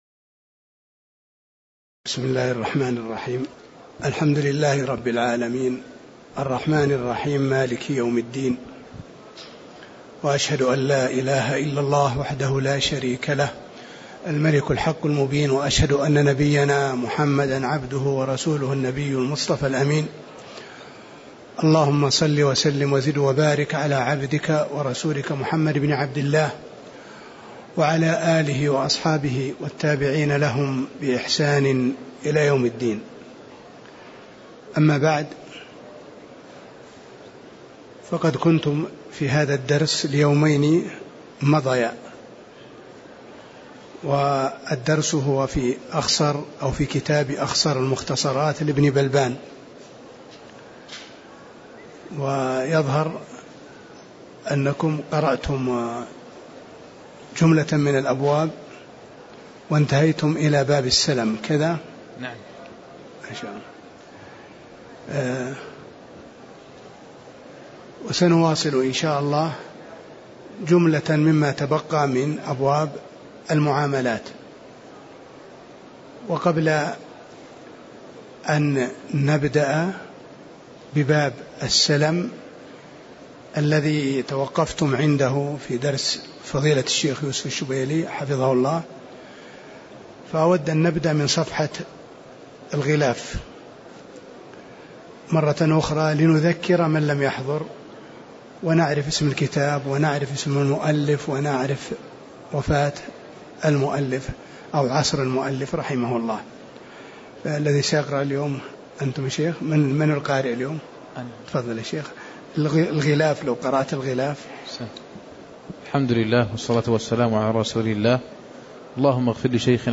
تاريخ النشر ١ ربيع الثاني ١٤٣٨ هـ المكان: المسجد النبوي الشيخ